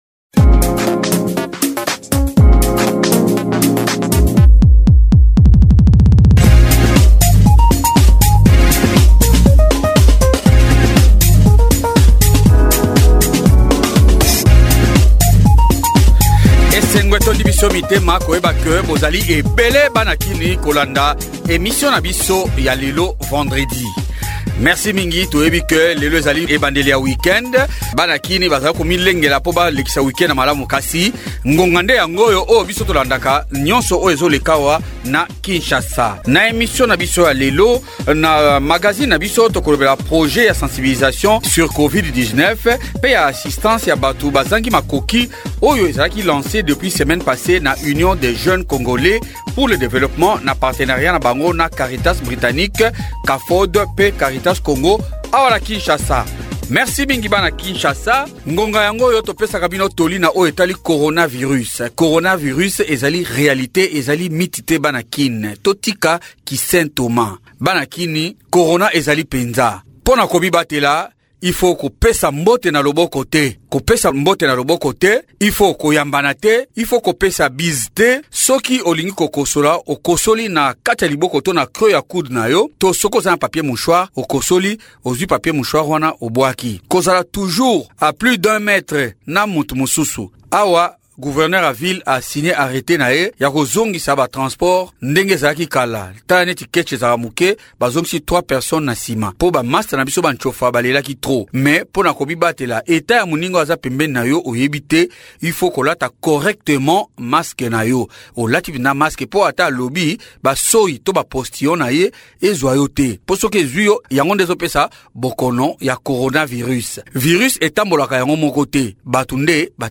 Invite: Charles Mbuta Muntu, ministre provincial et porte parole du gouvernamnet provincial de Kinshasa.